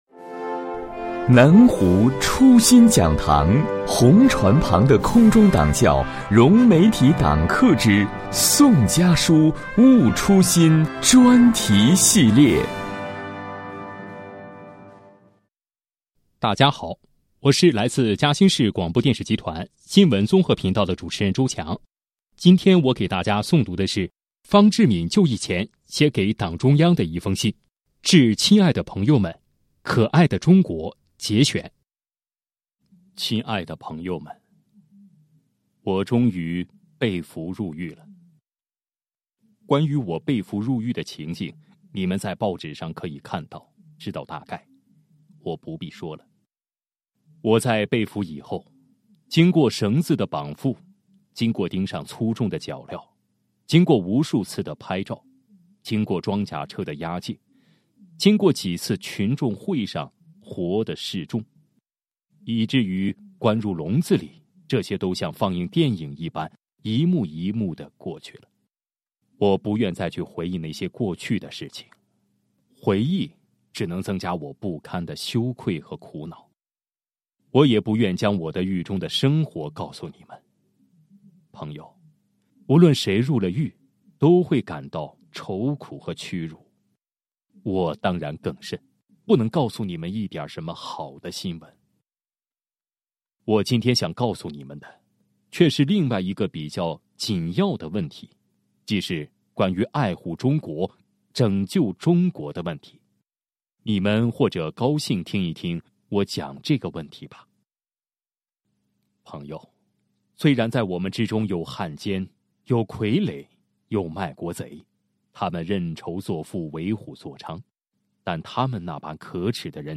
本期诵读